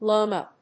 /ˈlomʌ(米国英語), ˈləʊmʌ(英国英語)/